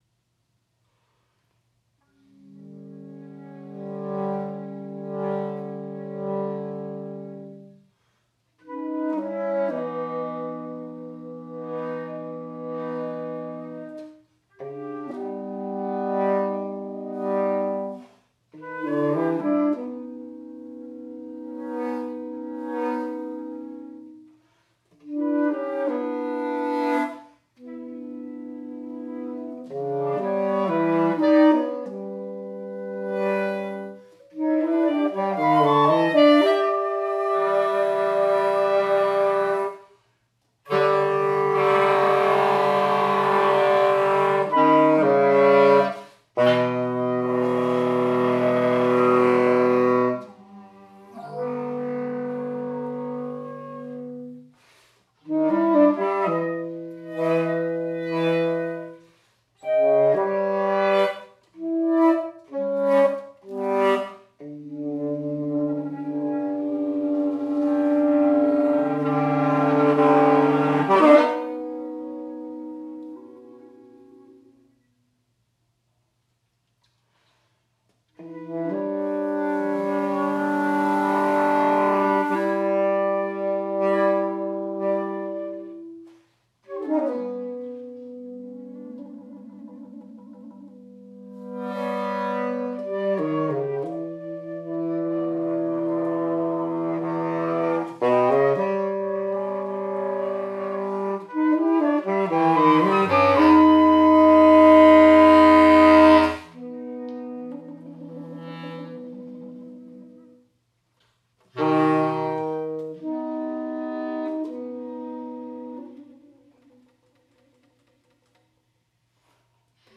Chamber
Oscillating Spheres (2019)Flute, Clarinet, Sax